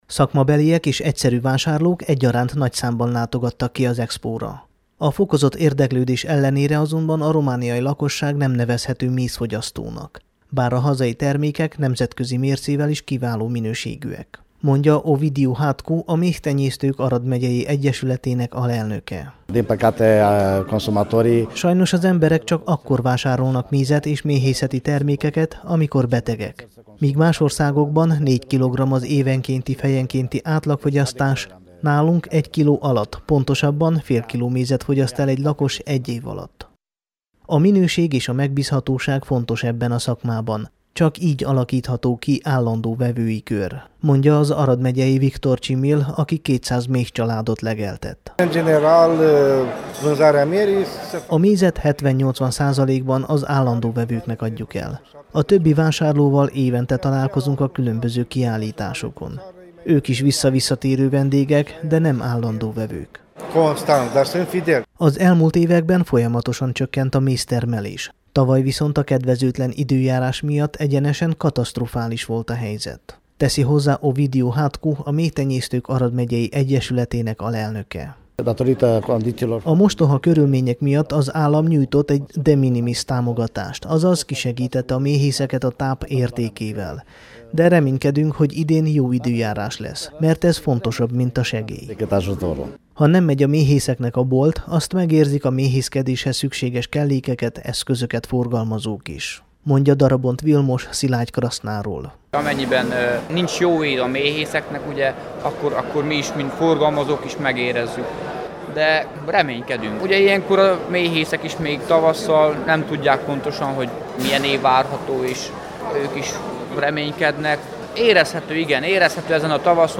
Első ízben szerveztek mézkiállítást és -vásárt Aradon.
mezkiallitas_radio.mp3